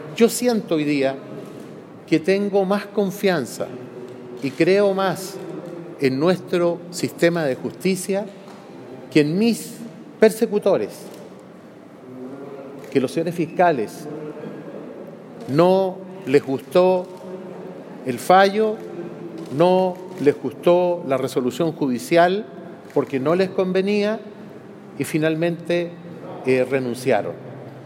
Tras la instancia, un enfático Moreira aseguró que, desde el primer momento en que se le relacionó con la causa, él fue el único en decir la verdad y que tal acción fue lo que permitió, esta jornada, que se haya hecho justicia.
“Yo jamás me he escondido detrás de una mentira”, señaló enfático.